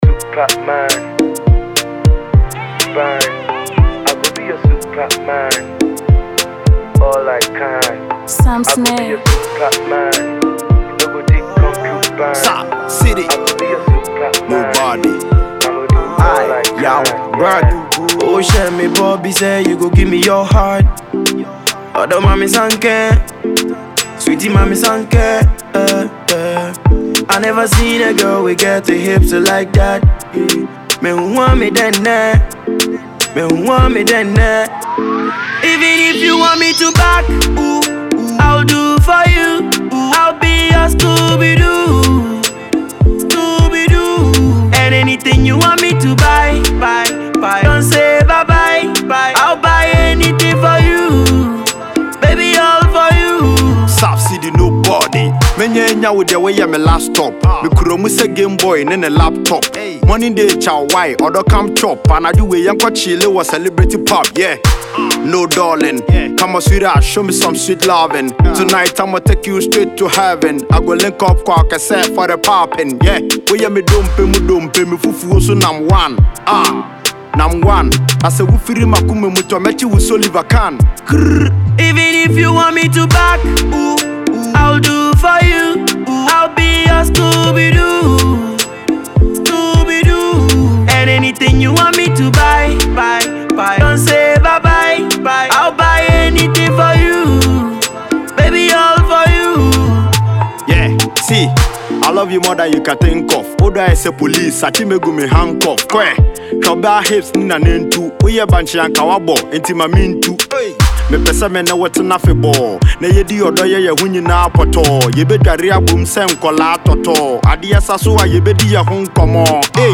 love jam